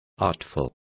Also find out how artera is pronounced correctly.